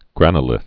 (grănə-lĭth)